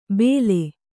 ♪ bēle